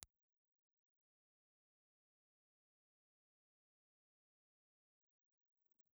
Nylon string guitar recorded with a Gaumont-Kalee 1492 ribbon microphone.
The mic sounds rather nice, rich and dark close up.